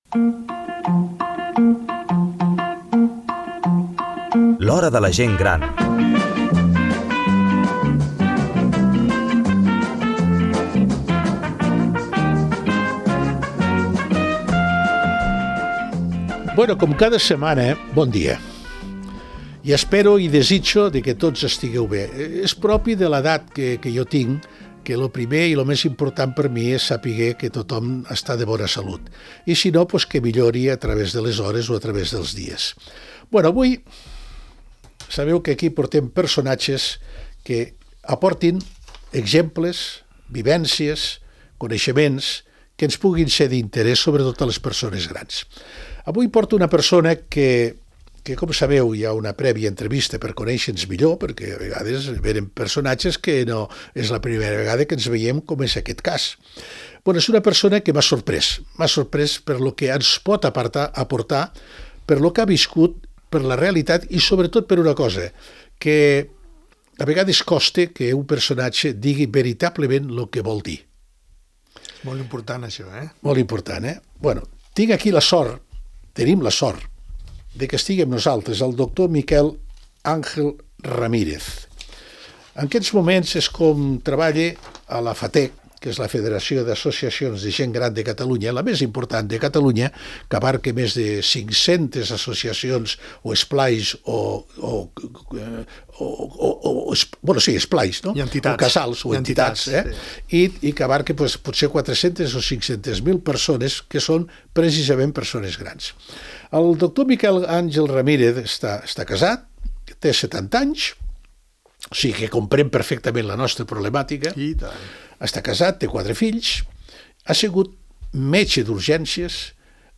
Un programa específic per a la Gent Gran